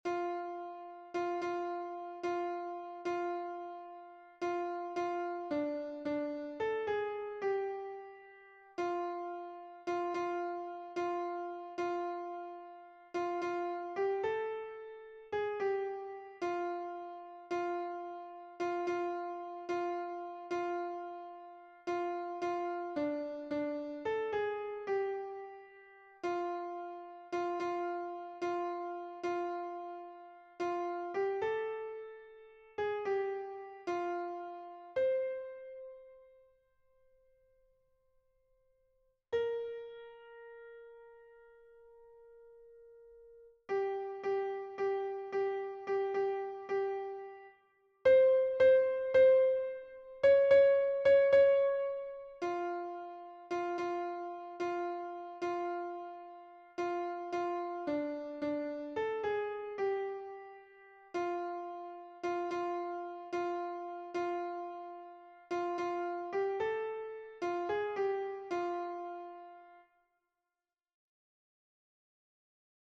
MP3 version piano